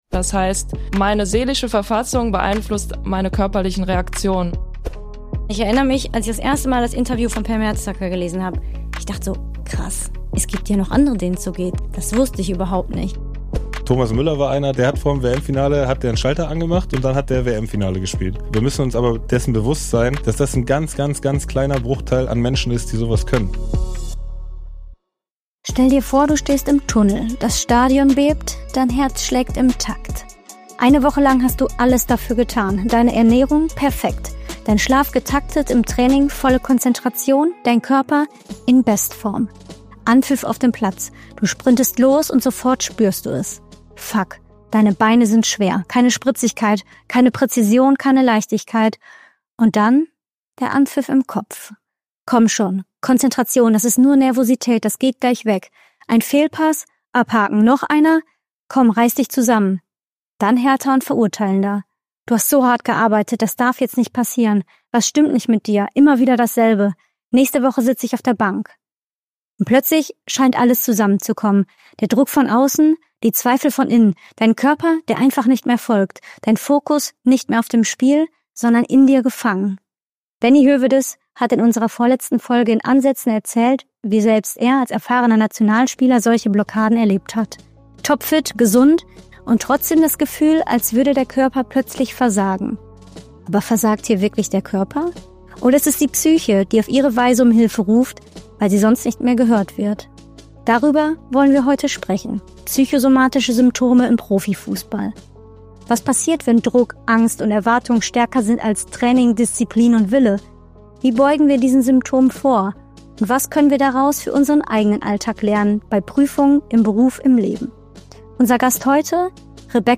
Hosts: Fabi Klos & Dr. Turid Knaak
Studio & Postproduktion: Foundation Room Studio